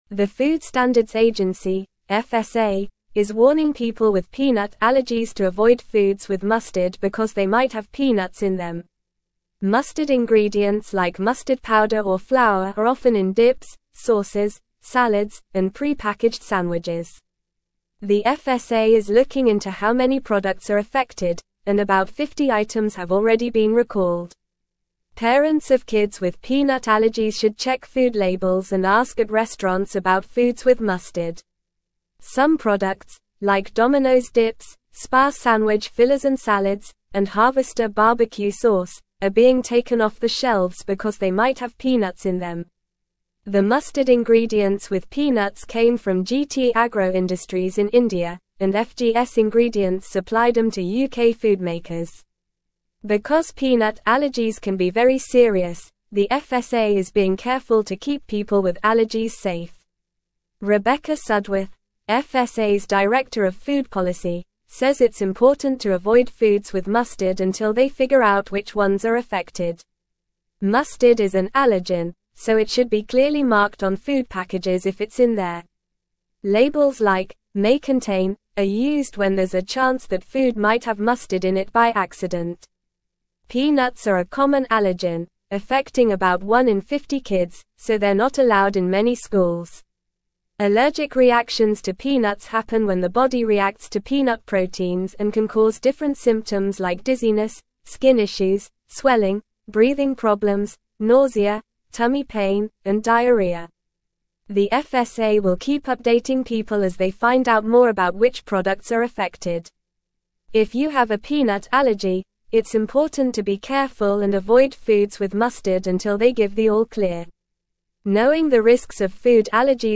Normal
English-Newsroom-Upper-Intermediate-NORMAL-Reading-FSA-Warns-of-Peanut-Contamination-in-Mustard-Products.mp3